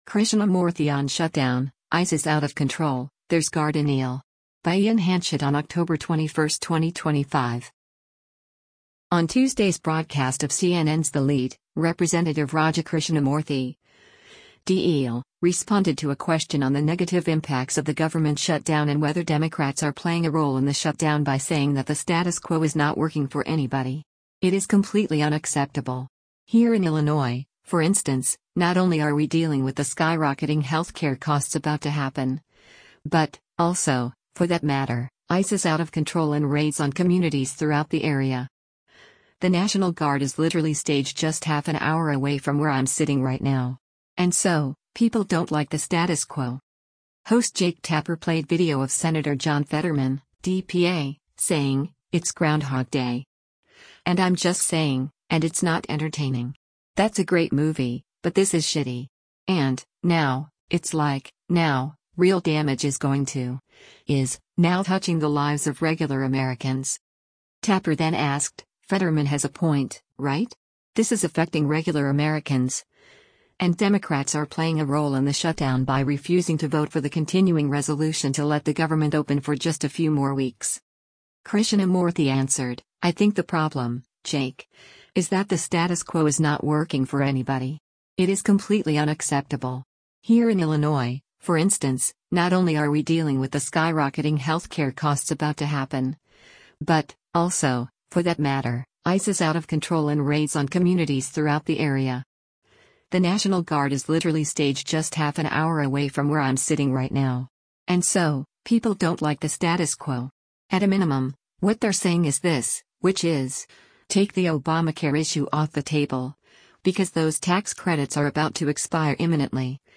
Host Jake Tapper played video of Sen. John Fetterman (D-PA) saying, “It’s ‘Groundhog Day.’ And I’m just saying — and it’s not entertaining. That’s a great movie, but this is shitty. And, now, it’s like, now, real damage is going to — [is] now touching the lives of regular Americans.”